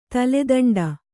♪ tale daṇḍa